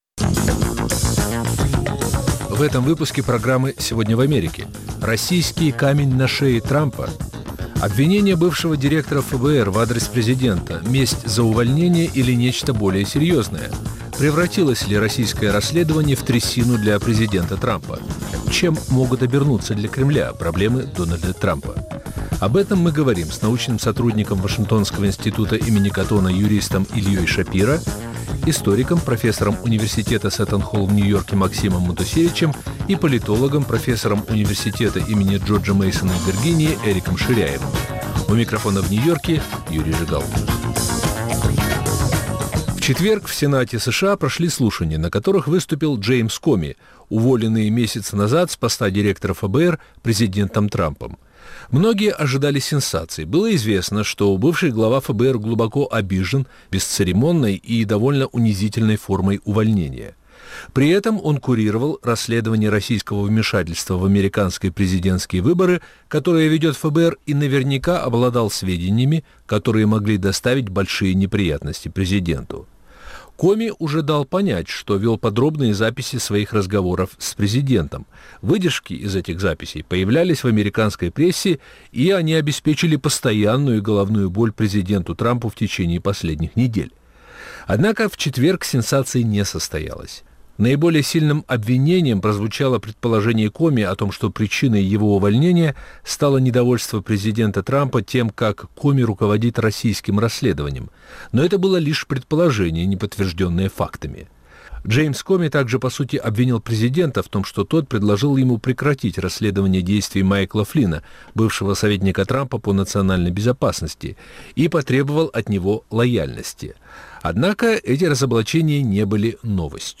обсуждают американские эксперты